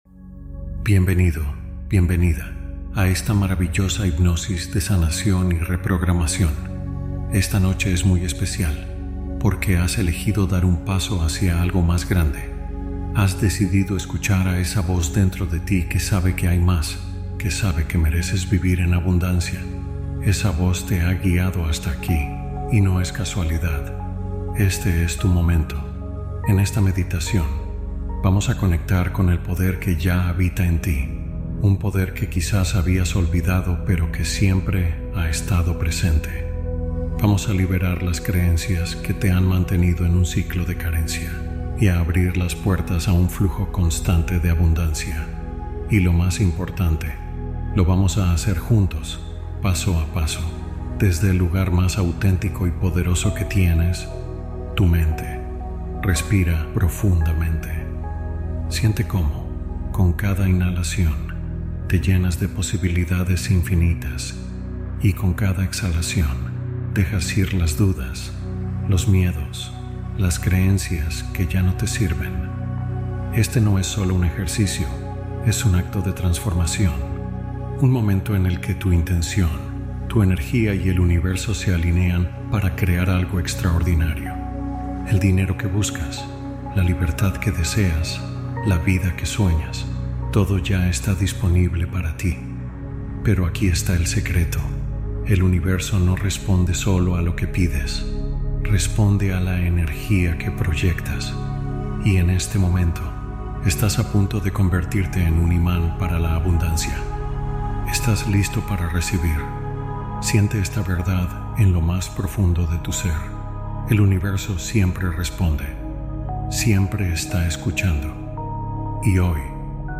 Hipnosis Para Recibir Abundancia Mientras Duermes